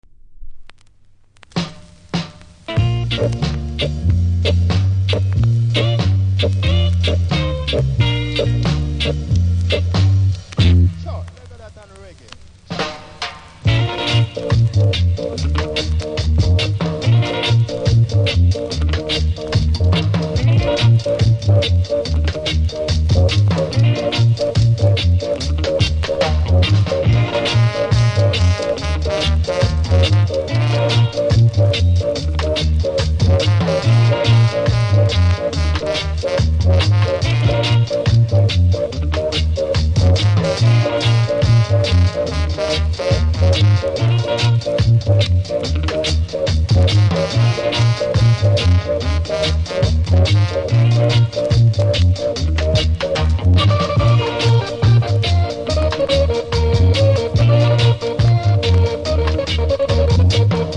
多少キズ多少ノイズありますがプレイは問題無いレベル。